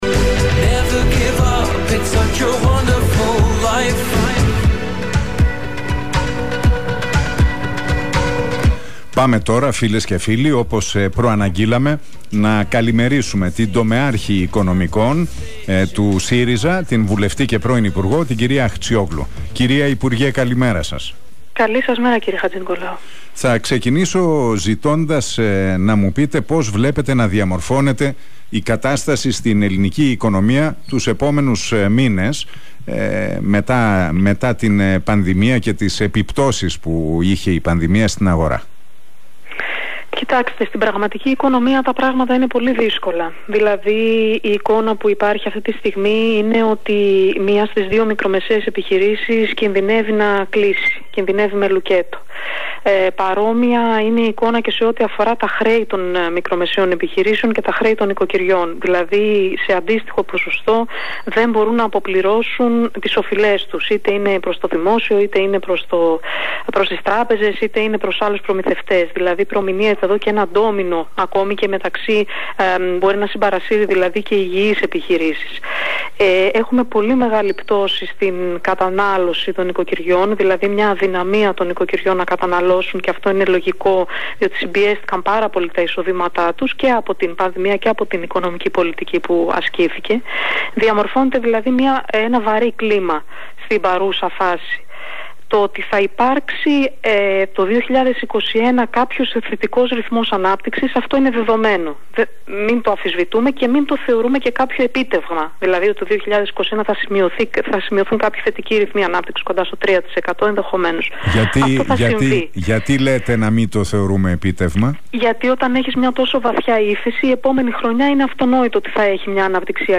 Αχτσιόγλου στον Realfm 97,8: Η κυβέρνηση δεν έχει σχέδιο επανεκκίνησης της οικονομίας
Η βουλευτής και τομεάρχης Οικονομικών του ΣΥΡΙΖΑ, Έφη Αχτσιόγλου...